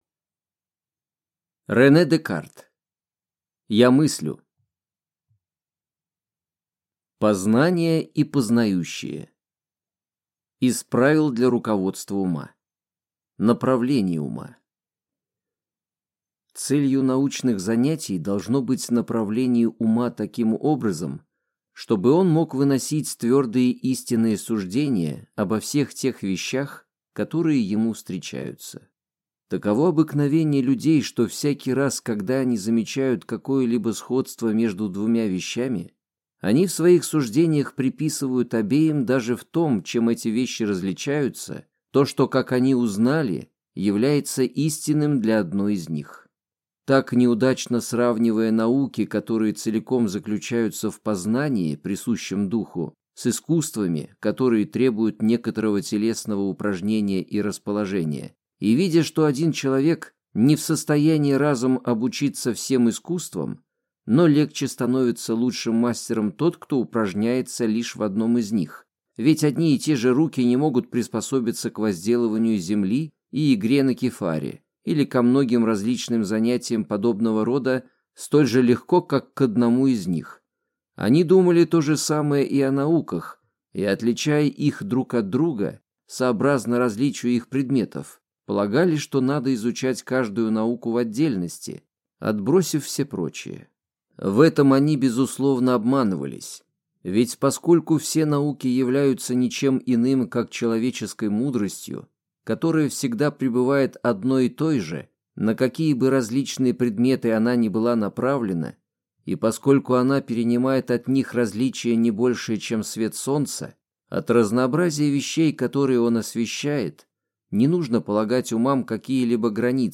Аудиокнига Я мыслю. Интеллект это страсть | Библиотека аудиокниг